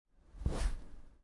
Download Movement sound effect for free.